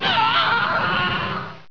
MUNCH1.WAV